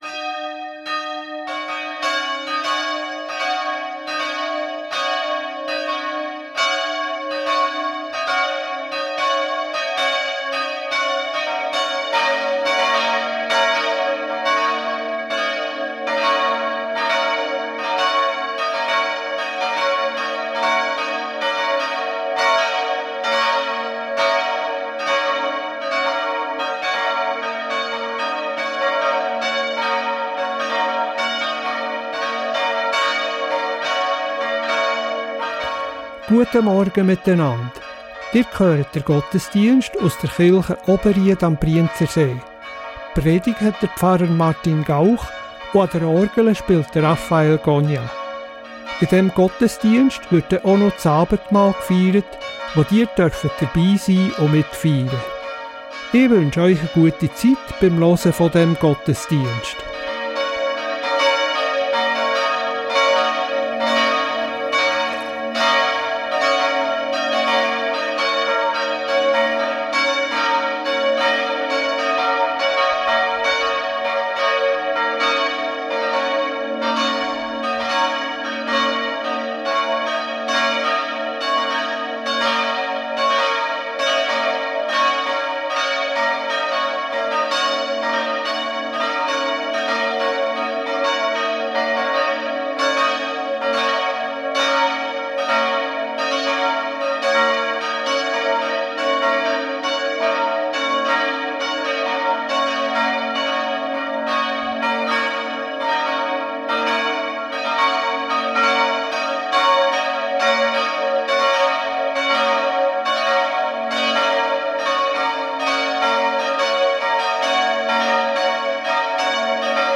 Reformierte Kirche Oberried am Brienzersee ~ Gottesdienst auf Radio BeO Podcast